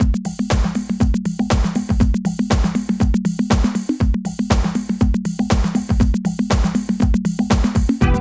78 DRUM LP-L.wav